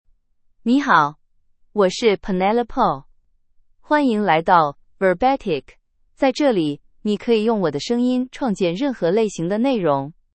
PenelopeFemale Chinese AI voice
Penelope is a female AI voice for Chinese (Mandarin, Simplified).
Voice sample
Listen to Penelope's female Chinese voice.
Female
Penelope delivers clear pronunciation with authentic Mandarin, Simplified Chinese intonation, making your content sound professionally produced.